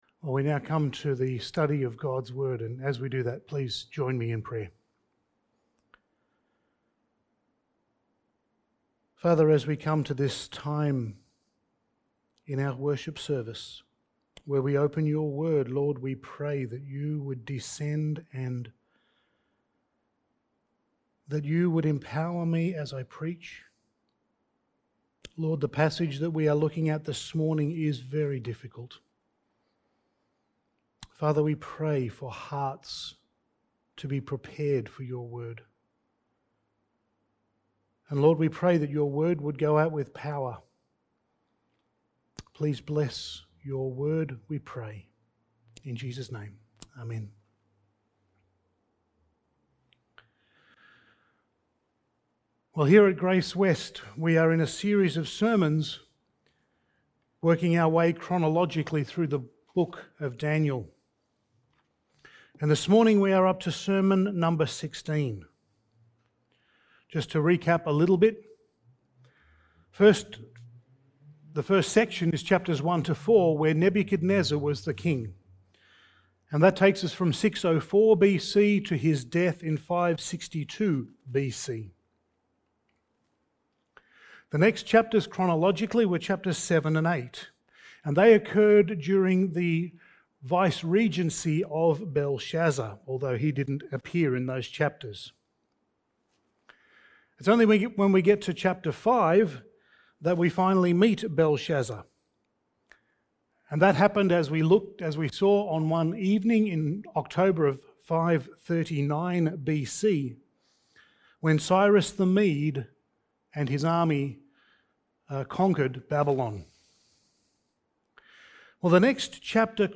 Passage: Daniel 9:1-19 Service Type: Sunday Morning